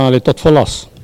Sallertaine
Catégorie Locution